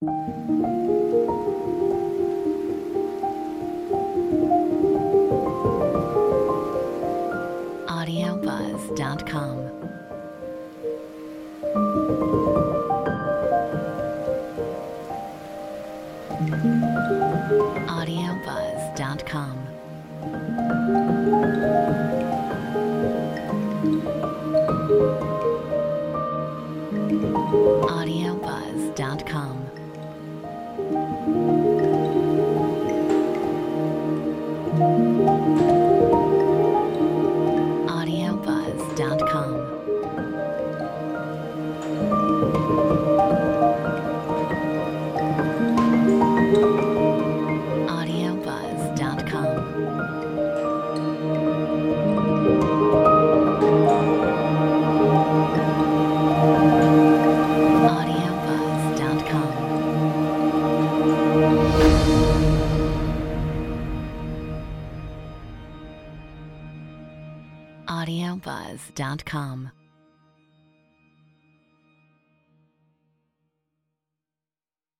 Metronome 50
A very intimate, and atmospheric soundscape.